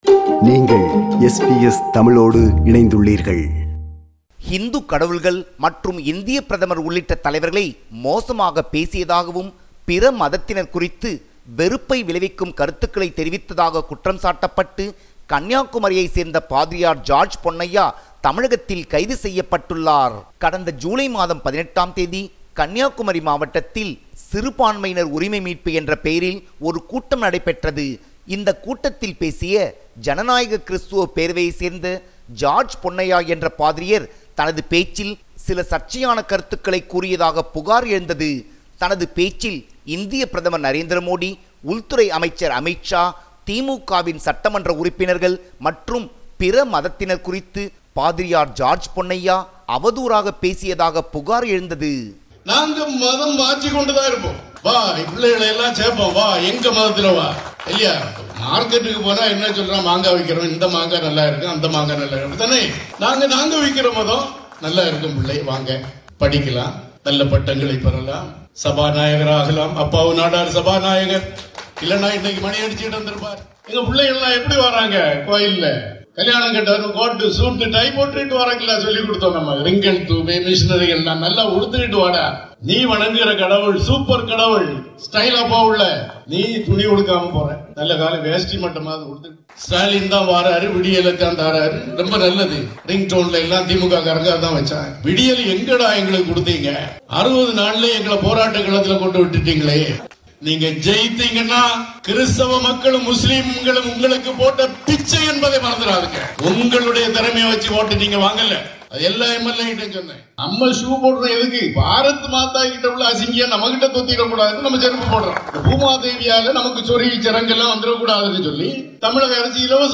பிற மதத்தினர் குறித்து வெறுப்பை விளைவிக்கும் கருத்துக்களையும் பேசியதாக குற்றம்சாட்டப்பட்ட அவரை தமிழக காவல்துறையினர் கைது செய்துள்ளனர். பல்வேறு இந்து அமைப்புகள் அவர் மீது புகார் அளித்துள்ள காரணத்தால் அவர் மீது ஏழு பிரிவுகளின் கீழ் காவல்துறையினர் வழக்கு பதிவு செய்துள்ளனர். கூடுதல் விவரங்களுடன் இணைகிறார் நமது தமிழக செய்தியாளர்